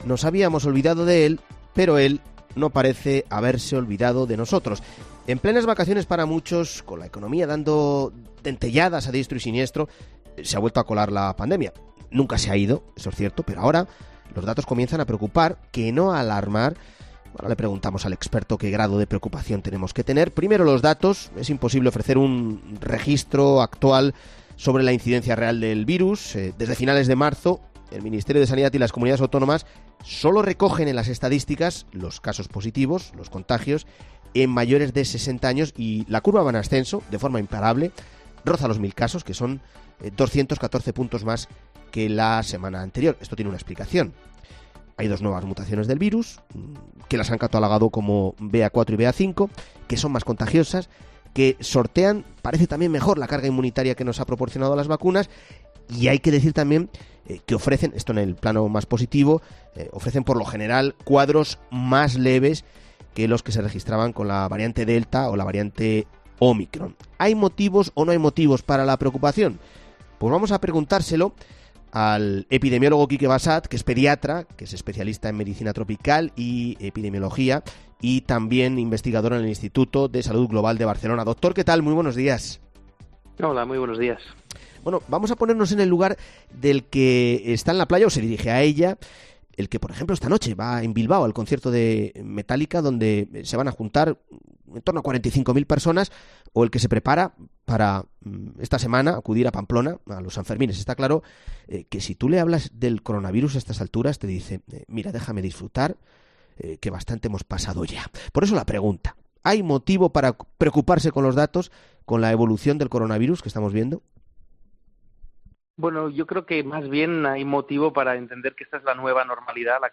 El epidemiólogo Quique Bassat habla claro sobre si debemos volver a las mascarillas en interior
El investigador del Instituto de Salud Global de Barcelona explica en COPE la diferencia clave entre las variantes AB.4 y AB.5 con la Ómicron